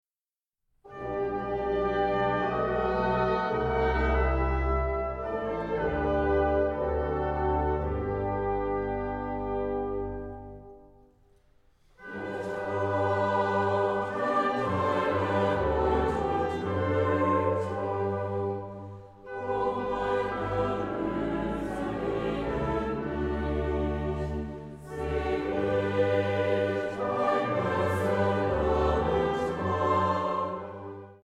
Chor, Bläser